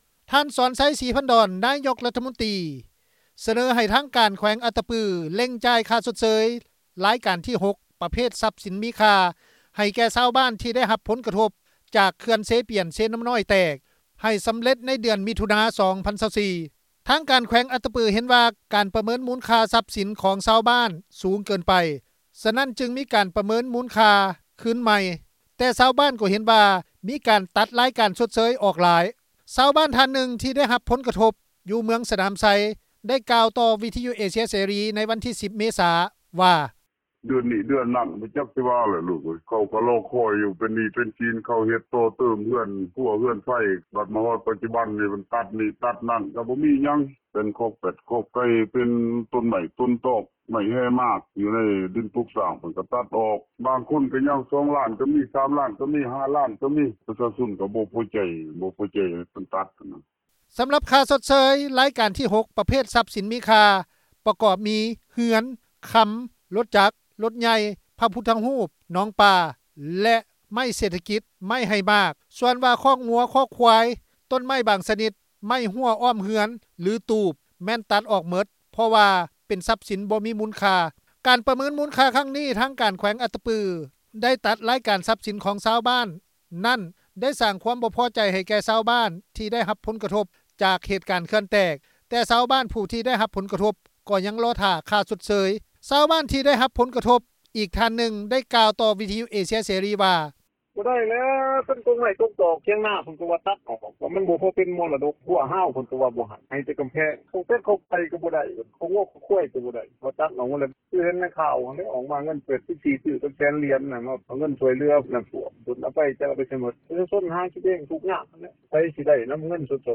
ຊາວບ້ານທ່ານໜຶ່ງ ທີ່ໄດ້ຮັບຜົນກະທົບຢູ່ເມືອງສະໜາມໄຊ ໄດ້ກ່າວຕໍ່ວິທຍຸເອເຊັຽເສຣີ ໃນວັນທີ 10 ເມສາ ວ່າ:
ຊາວບ້ານທີ່ໄດ້ຮັບຜົນກະທົບ ອີກທ່ານນຶ່ງ ໄດ້ກ່າວຕໍ່ວິທຍຸເອເຊັຽເສຣີ ວ່າ:
ເຈົ້າໜ້າທີ່ທ້ອງຖິ່ນໄດ້ ທ່ານນຶ່ງ ກ່າວຕໍ່ວິທຍຸເອເຊັຽເສຣີ ໃນວັນທີ 10 ເມສາ ວ່າ: